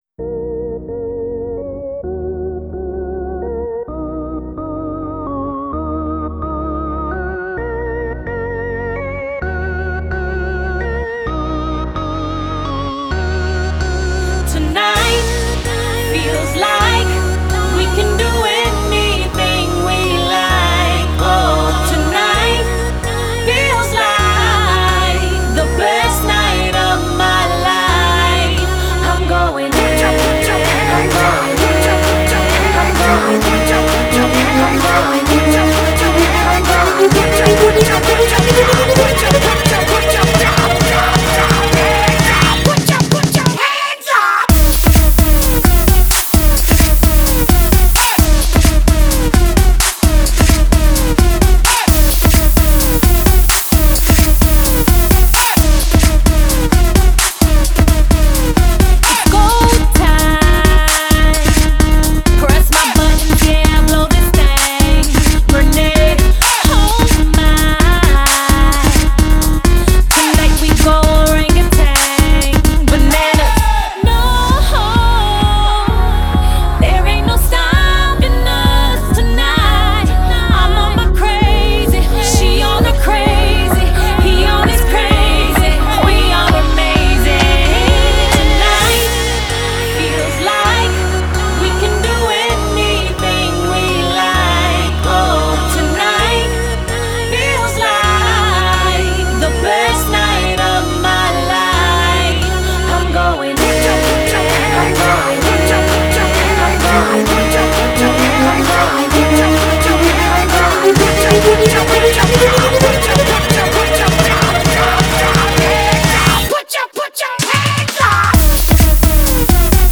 Genre: Soundtrack.